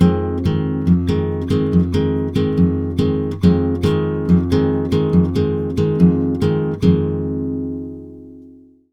140GTR FM7 1.wav